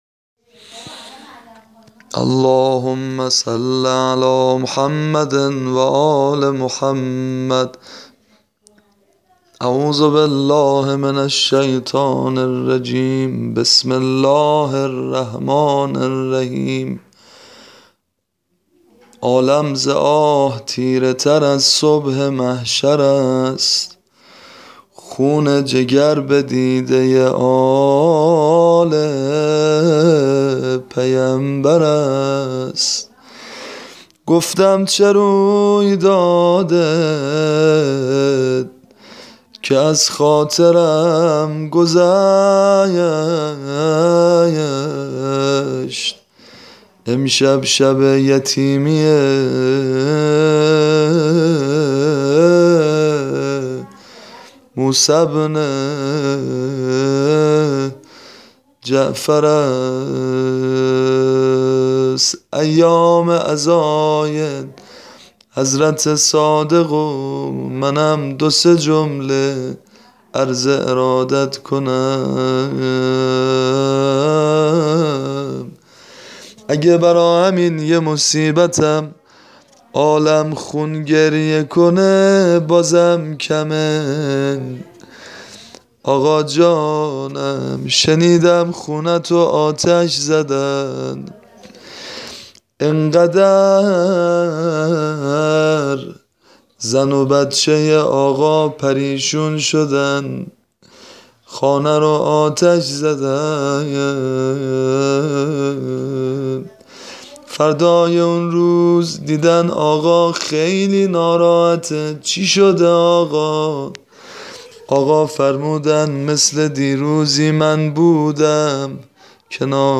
واقعا صداشون سوز داره